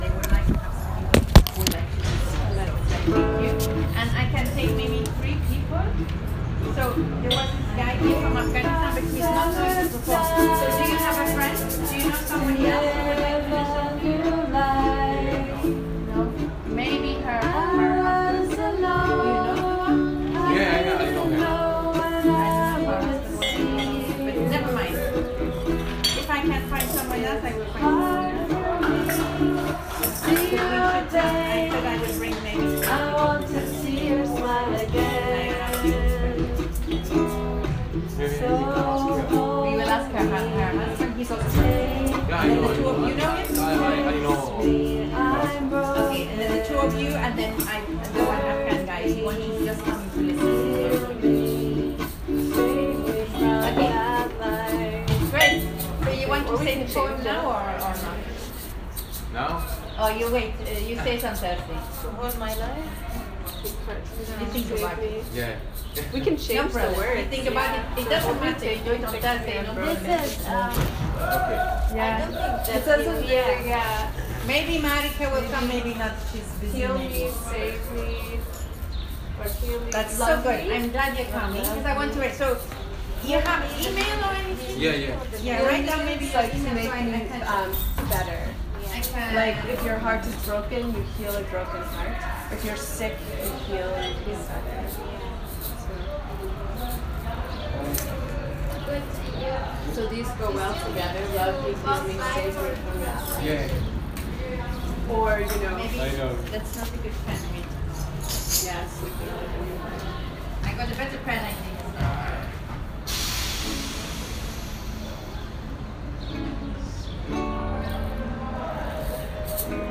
These are recordings from the center that show the process of writing the song:
the-sun-will-come-again-writing-the-song01.m4a